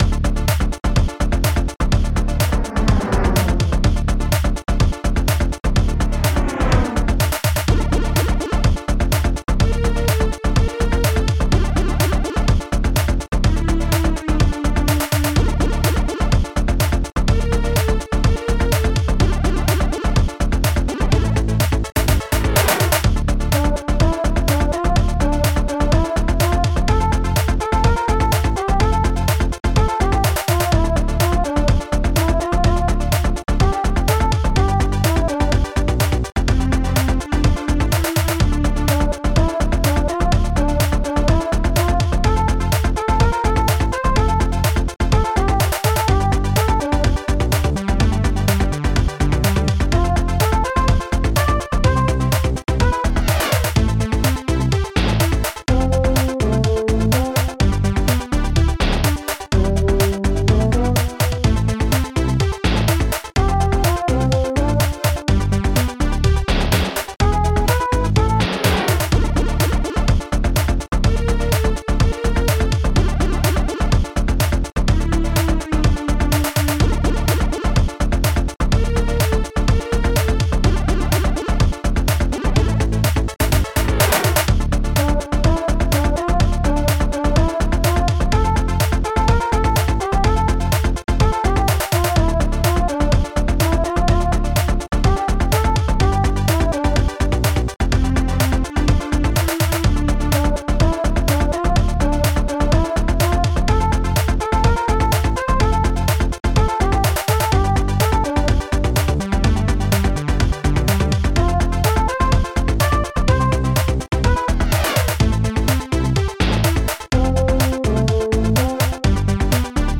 Protracker Module
strings6major fatyambazz seriousdrum snare.hard shadowshaker housesynth breath Brass5